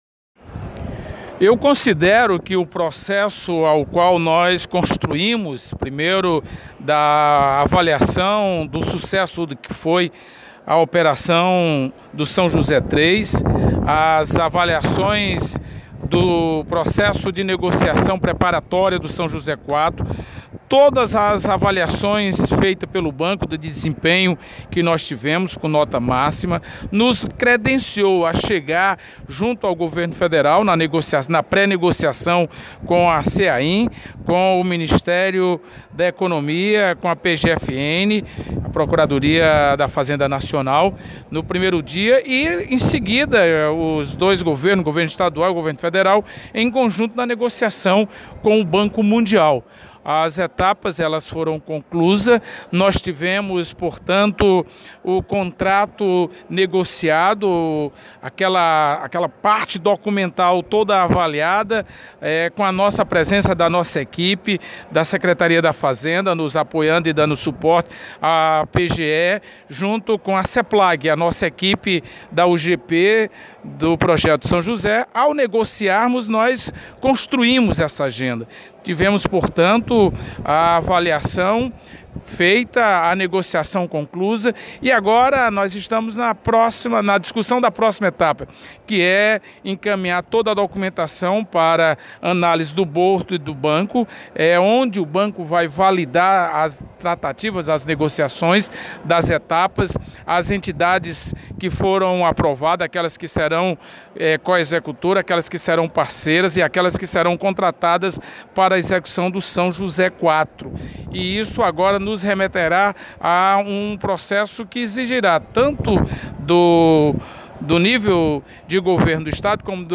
De Assis Diniz, Secretário do Desenvolvimento Agrário do Ceará